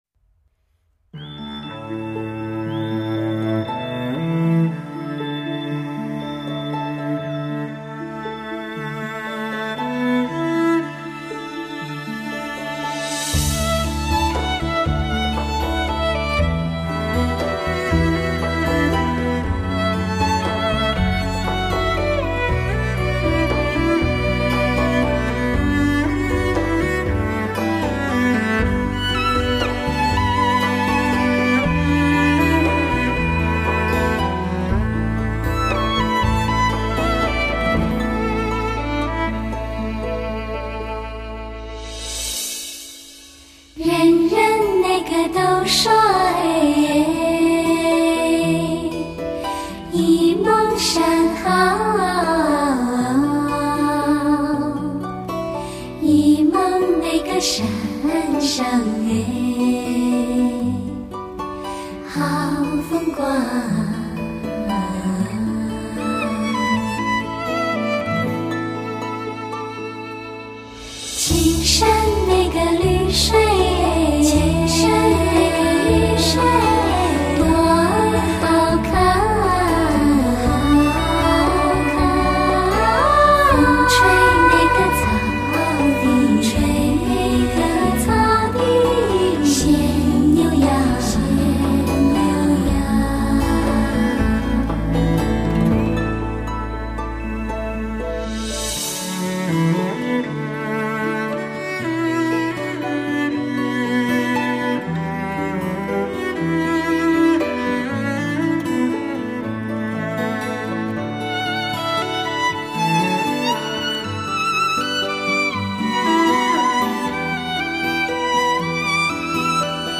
在传承前四张专辑风格：经典传颂的歌曲，飘逸悠扬的女声，张弛有度的配乐，
中，歌曲开始和结束时都由浑厚的男中音领唱，这现象在一张女子合唱专集中出现很有意思，男中音的深沉更能显出女声的细腻绵长，